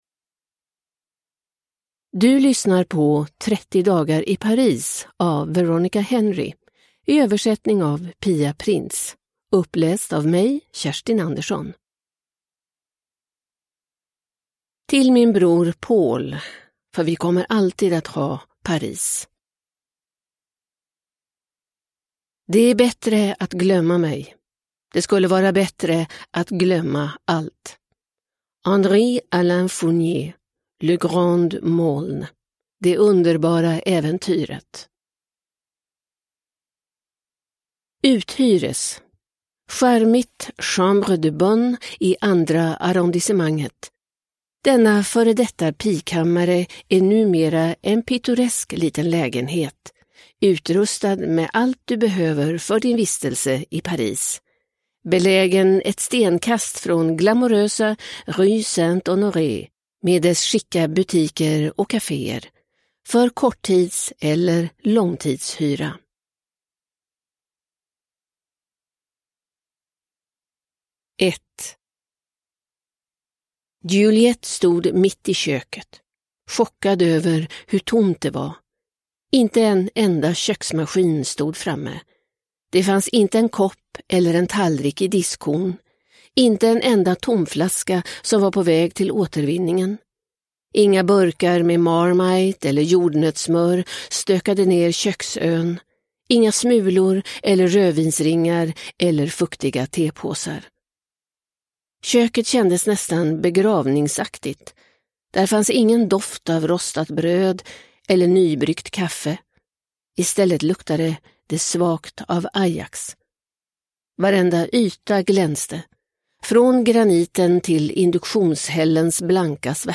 30 dagar i Paris – Ljudbok – Laddas ner